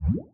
splash-slime.ogg